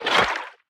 Sfx_creature_symbiote_bite_01.ogg